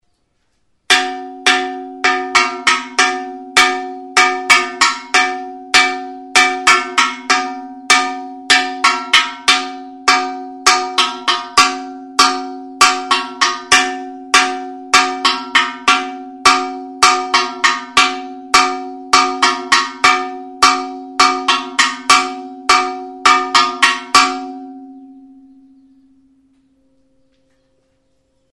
Music instrumentsKUGE; Campanas de llamada
Idiophones -> Struck -> Directly
Recorded with this music instrument.
Makila bat du kanpaiak jotzeko.